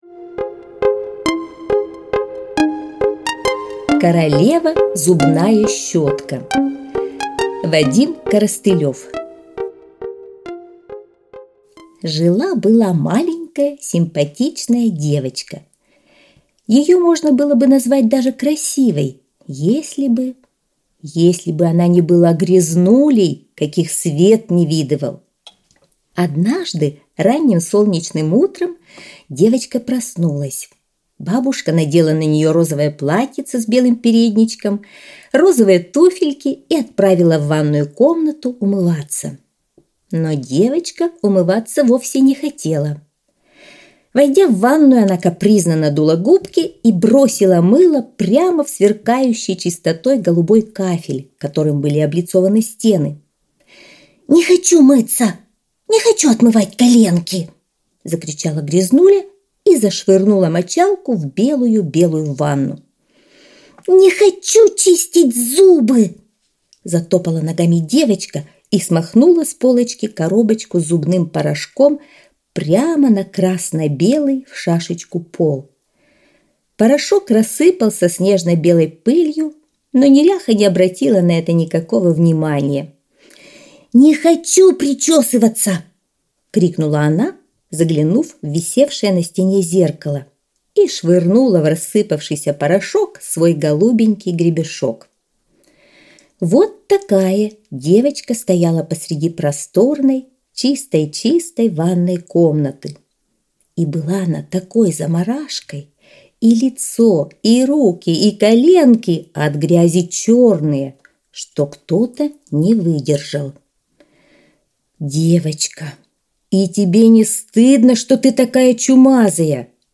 Королева зубная щетка - аудиосказка Коростылева - слушать онлайн